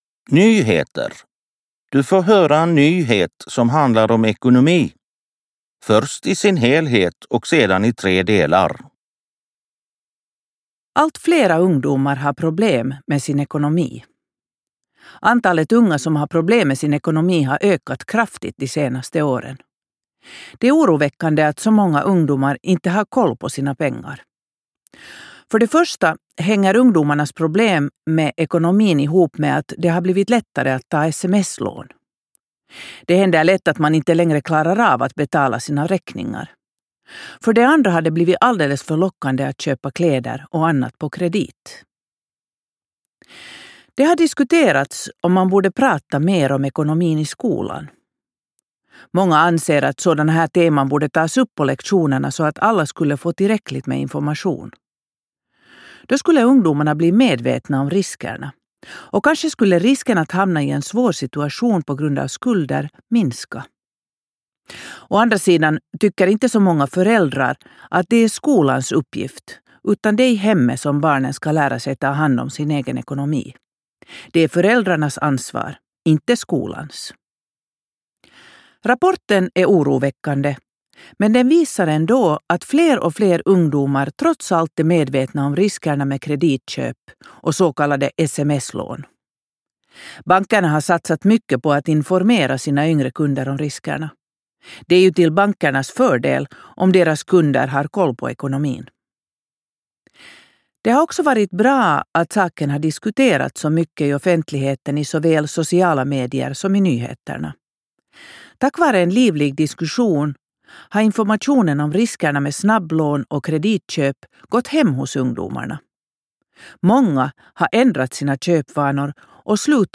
15_Fokus_4_Pengar_och_konsumtion_Nyheter.mp3